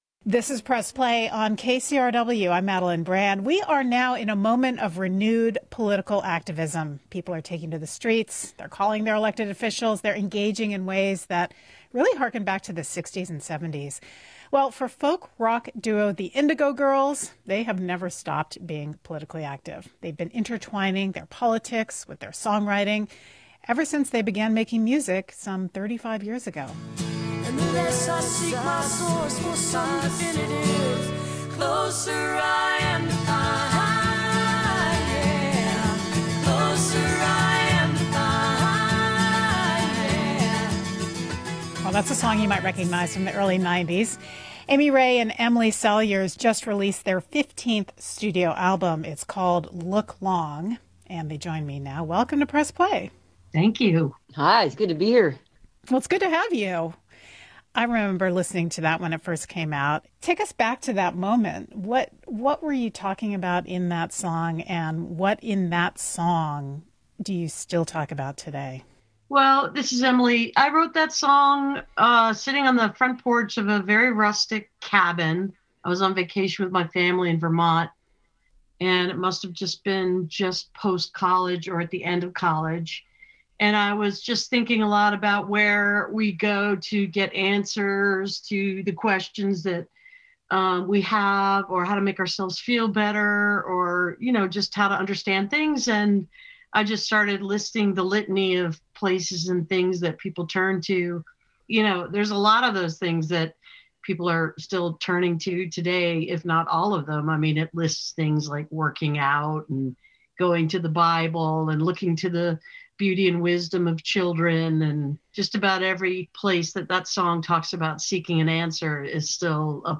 02. interview (5:23)